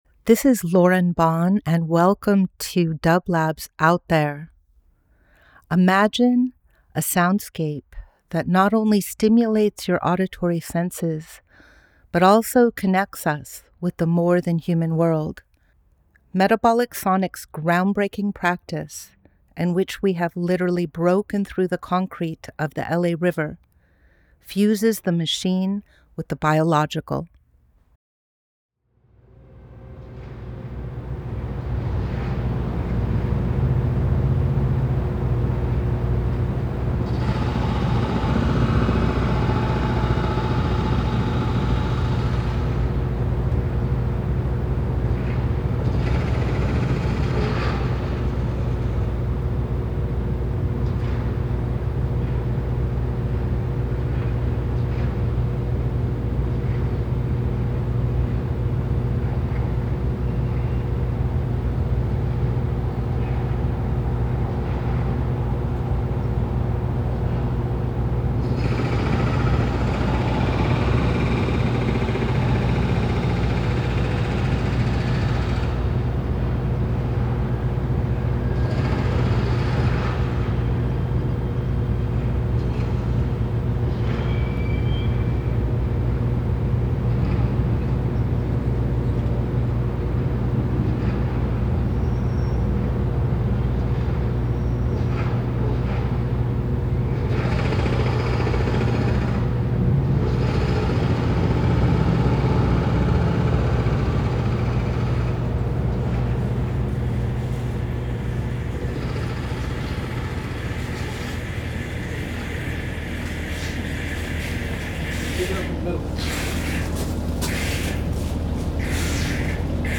Out There ~ a Field Recording Program
Each week we present a long-form field recording that will transport you through the power of sound. Tune-in, open up, and venture out there as we take excursions into evocative audio fields together.
Metabolic Studio Out There ~ a Field Recording Program 09.04.25 Ambient Experimental Field Recording Voyage with dublab and Metabolic into new worlds.